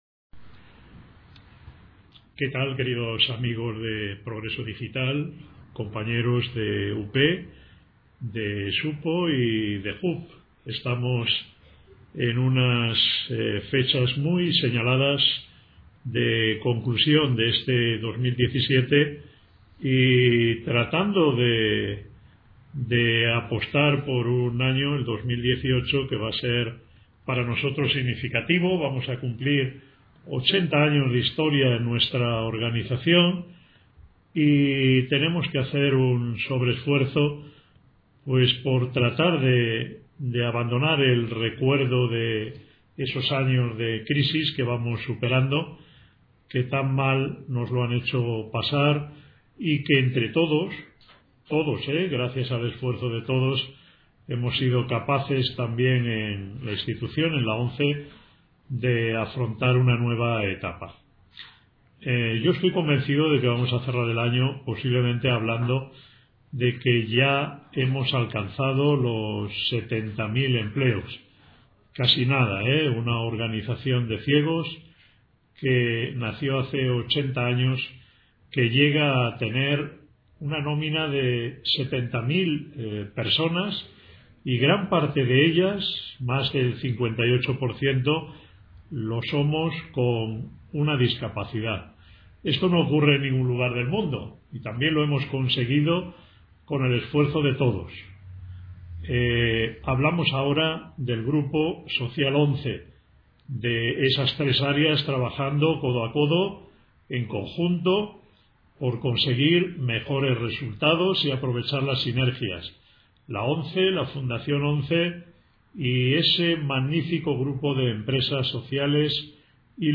Miguel Carballeda, Presidente de UP y del Grupo Social ONCE, felicita las navidades a todos los asociados y simpatizantes de UP y hace un breve balance del año en este corte sonoro para Progreso Digital
Felicitación Navidad Miguel Carballeda Presidente de UP.mp3